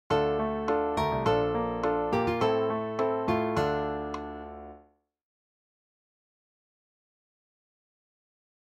Dm  Bbmaj7  Am  Dm [